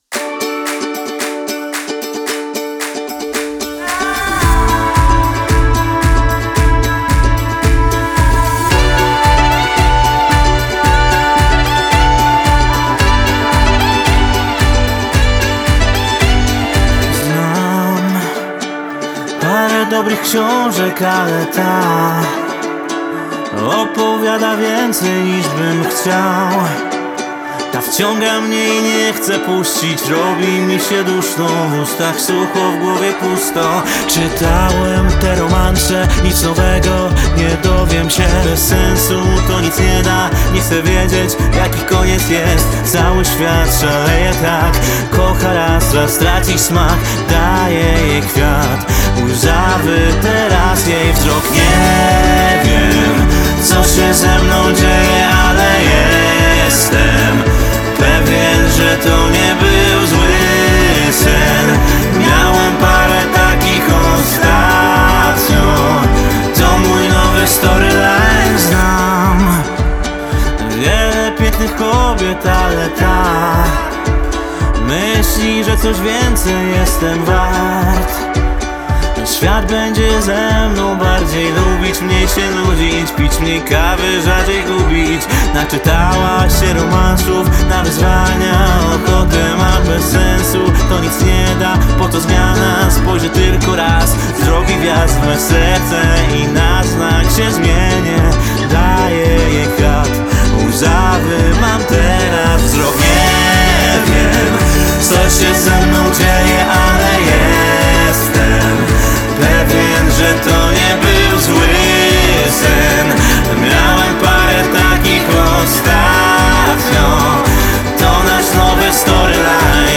Gatunek: melodyjna, radiowy lekki pop
Utrzymana w folkowo- popowym klimacie